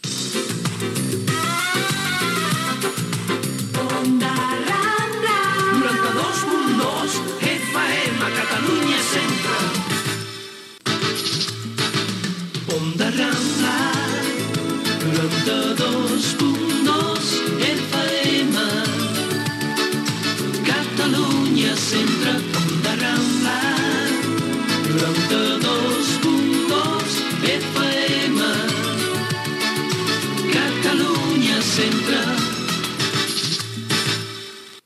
Indicatiu cantat de l'emissora
FM